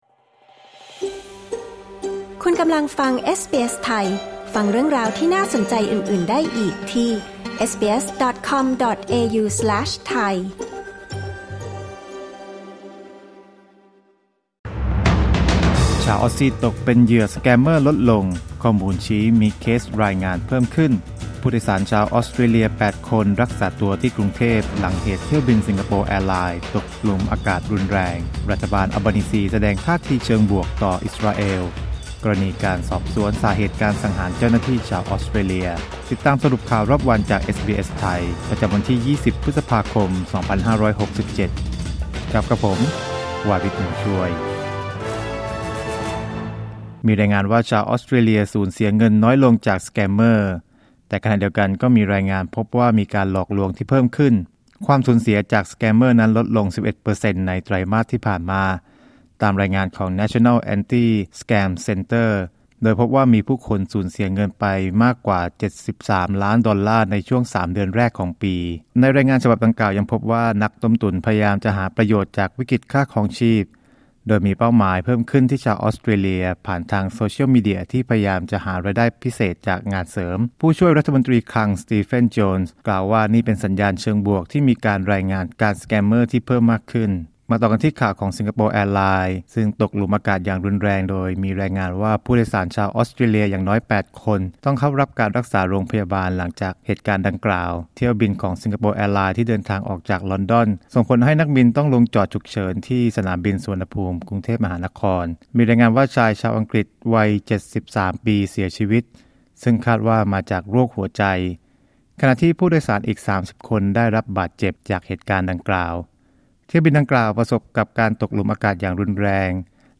สรุปข่าวรอบวัน 22 พฤษภาคม 2567